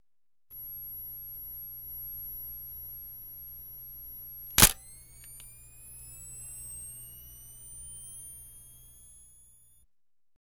Звуки вспышки фотоаппарата
Звук автофокуса и скоростной серийной съемки